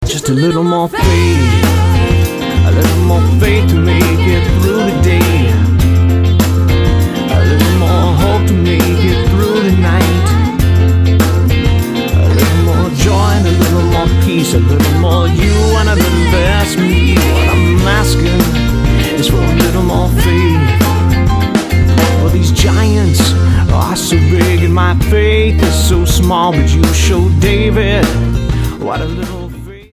STYLE: Roots/Acoustic
An authentically loose and rootsy collection